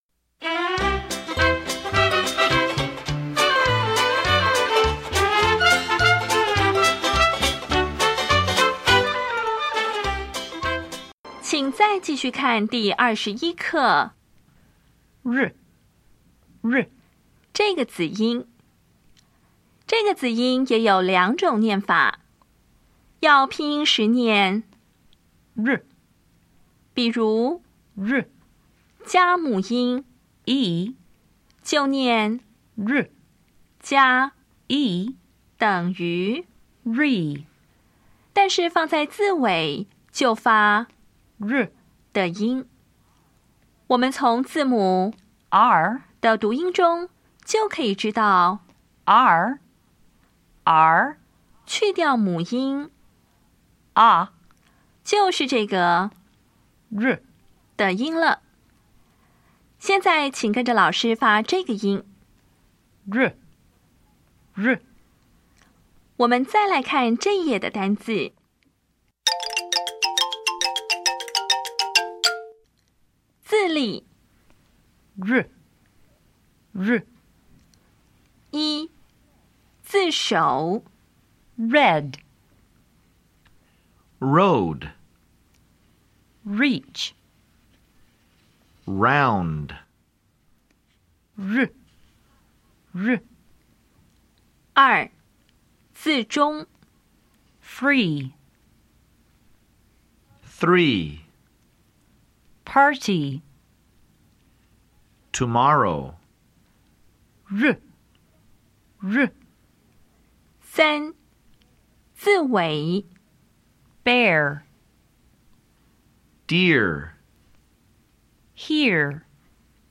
当前位置：Home 英语教材 KK 音标发音 子音部分-2: 有声子音 [r]
音标讲解第二十一课
[rɛd]
[raʊnd]